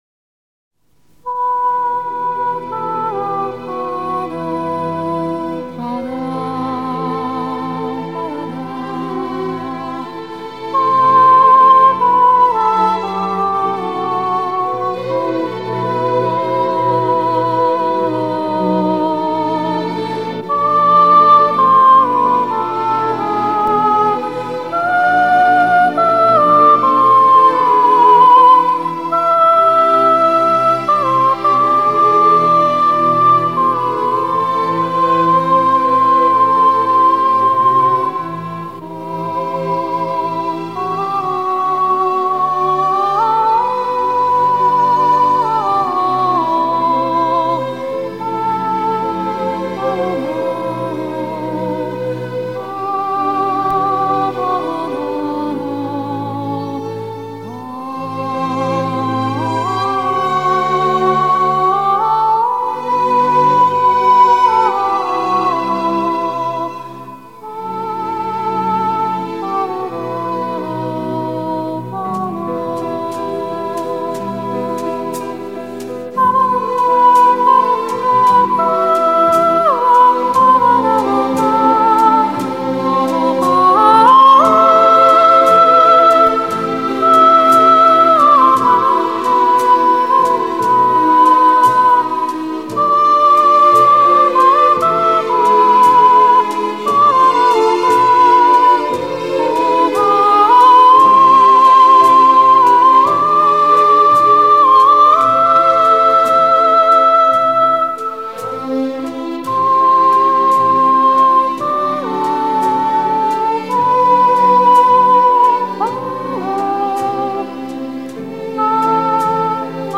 В оркестровом имеется только такой
Такая инструментальная версия красивого вальса